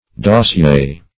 Dossier \Dos`sier"\ (d[-o]s`sy[asl]"; E. d[o^]s"s[i^]*[~e]r), n.